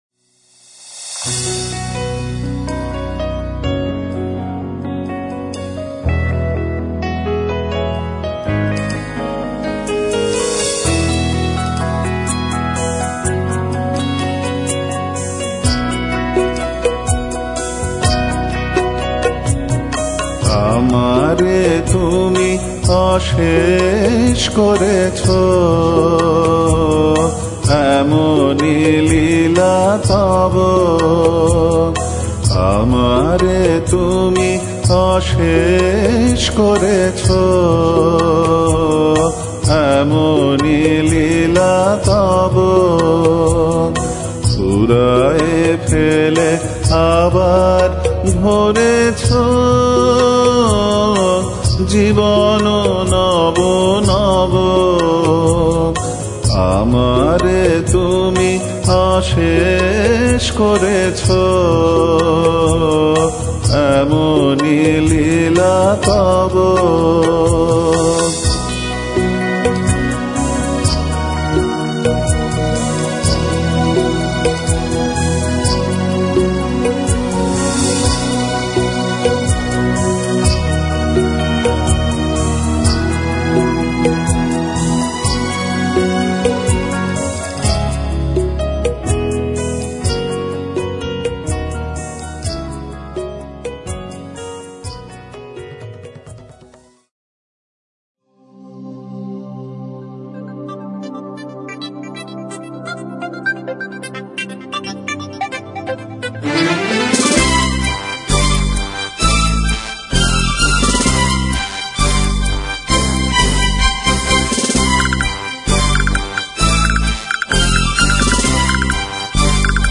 Rabindra Sangeet
from the dulcet voice